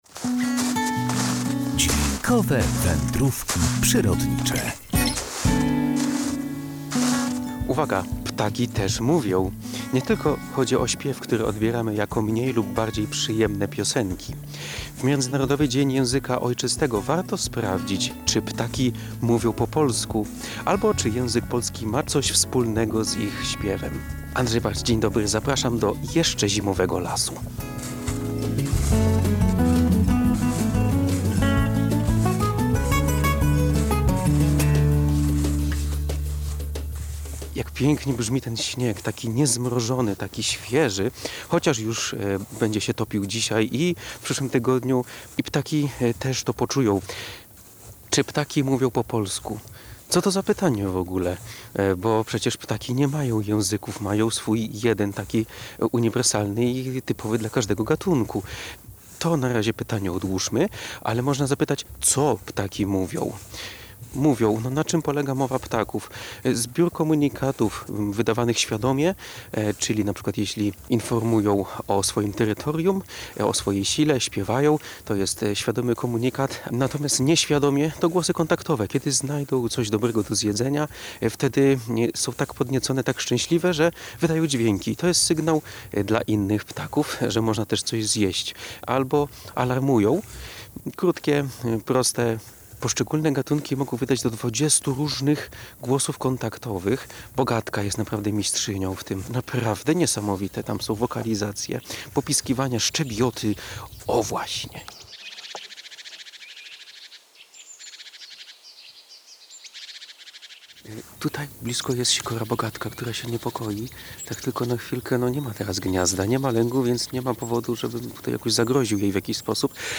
sprawdzał w lesie, czy ptaki mówią po polsku i czy język polski ma coś wspólnego z ich śpiewem.